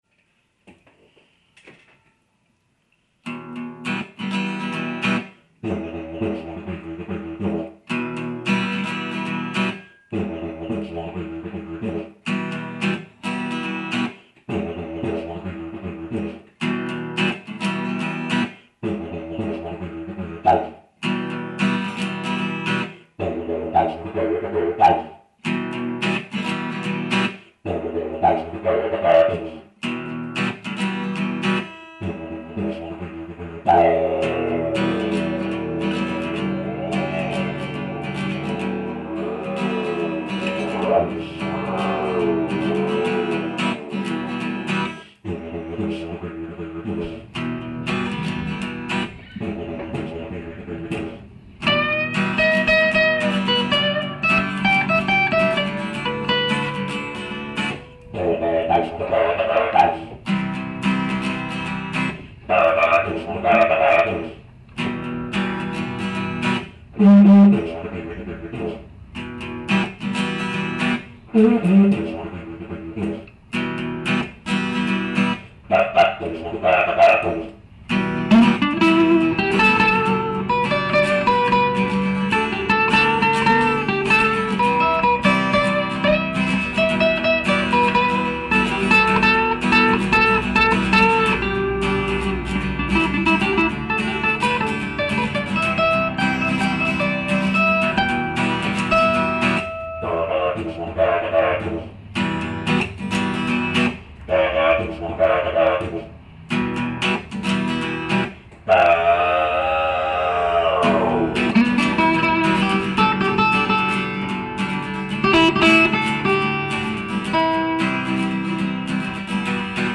Session didj+guitare (23.06.05)
Un didj, deux grattes.